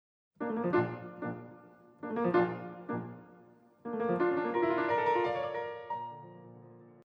Take the beginning of the Sonata op. 22 as an example of the opposite, a “start-and-stop” beginning: